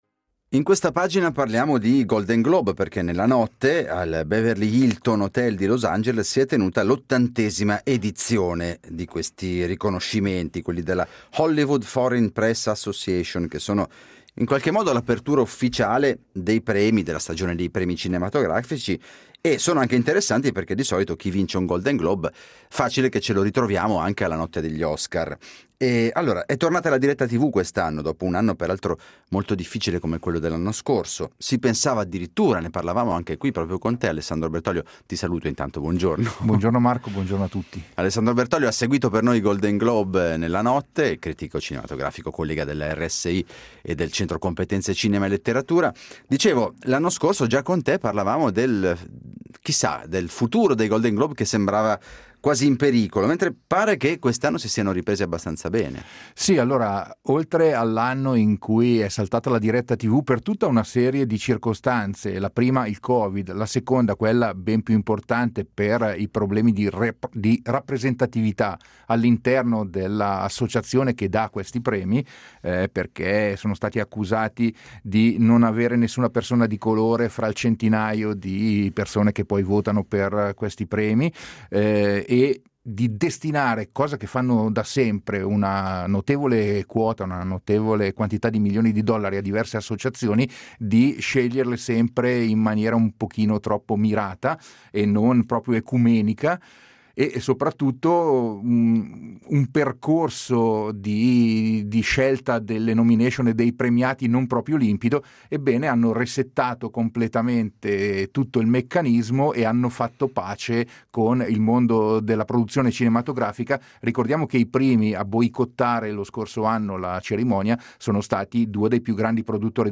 Ne parliamo con l’autore.